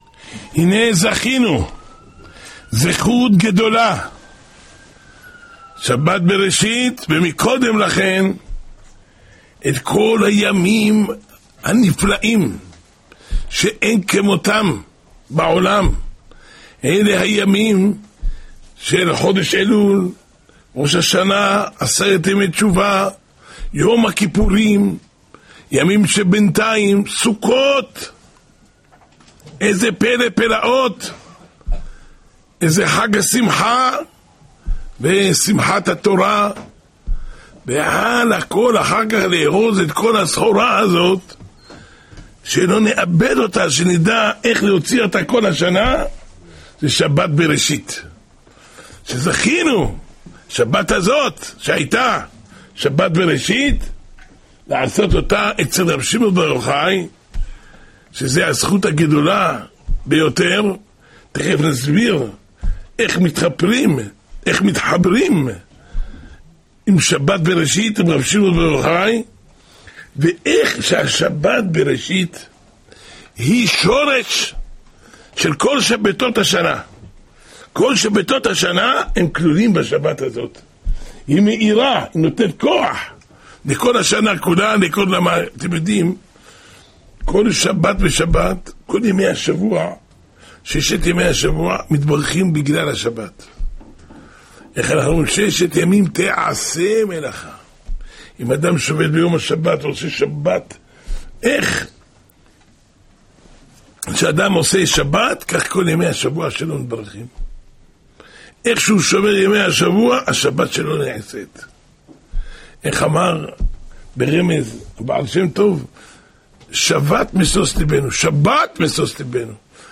השיעור השבועי